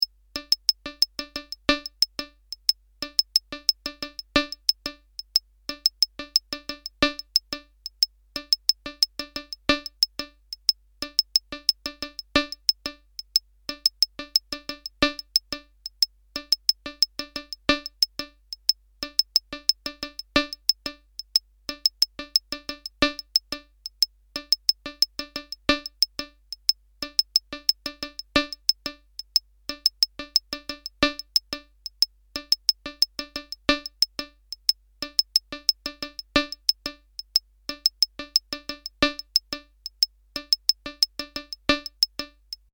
Bucle de percusión electrónica
Música electrónica
melodía
repetitivo
sintetizador